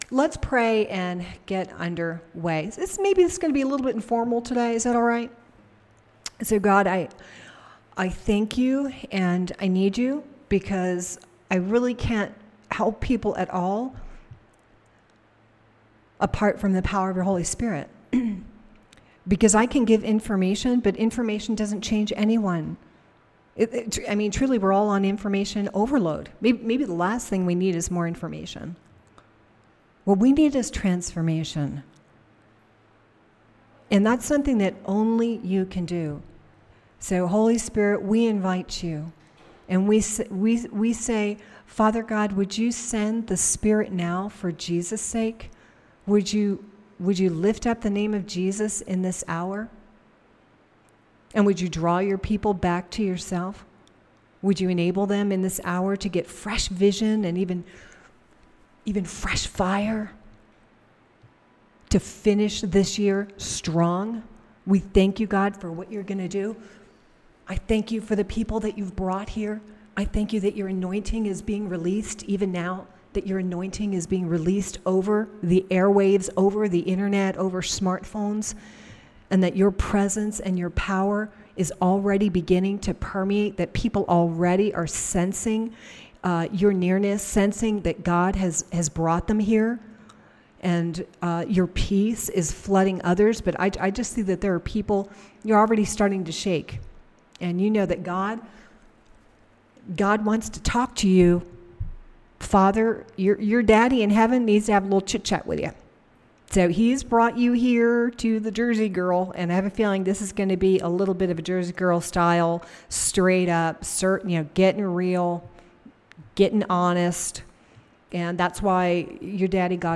(audio features entire teaching)